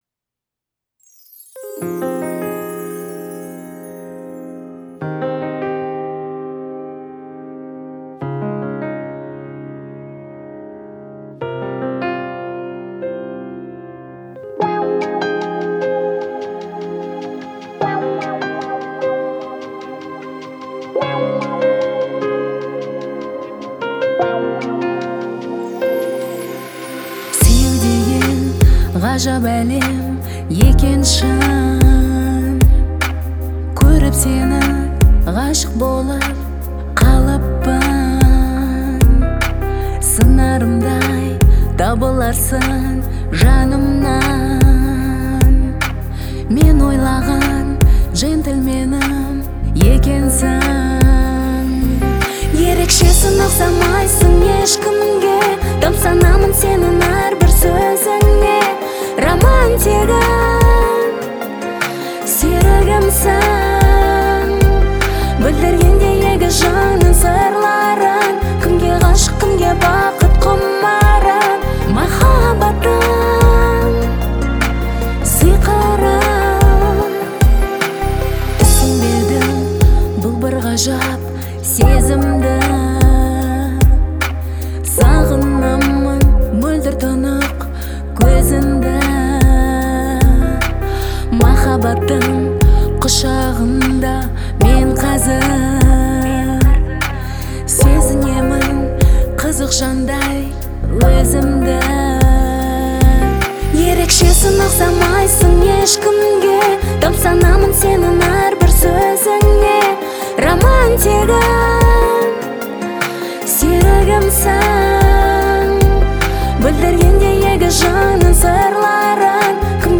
казахского поп-фолка